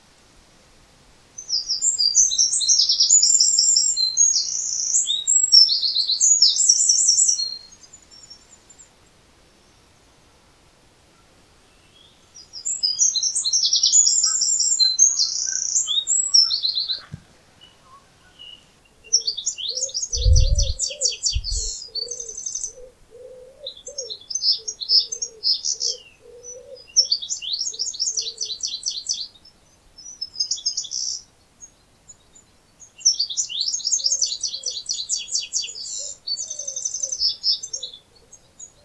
A little later, now in the lee of the Shirlett woodlands, it’s calmer. Down by Lower Pool, first a wren and then a goldfinch make their presence known (along with the inevitable canada goose and woodpigeon (click the ‘play’ button below), and there are grey wagtails hopping about by the water’s edge